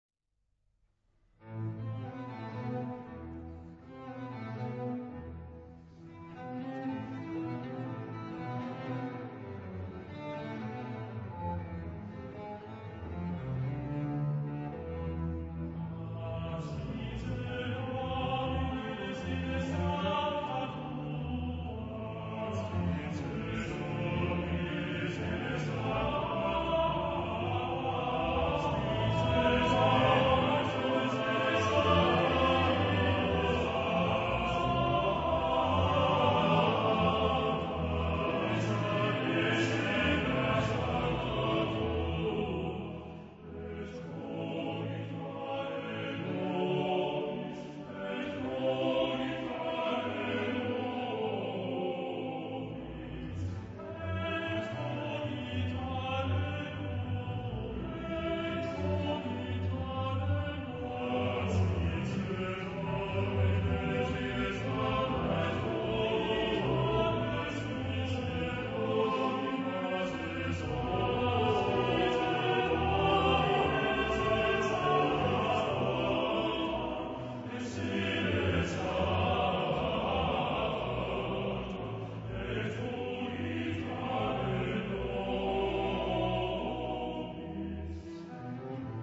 Genre-Style-Forme : Romantique ; Sacré ; Cantate
Type de choeur : TTBB  (4 voix égales d'hommes )
Solistes : Tenors (2) / Basses (2)  (4 soliste(s))
Instruments : Basse continue
Tonalité : la majeur ; la mineur ; fa majeur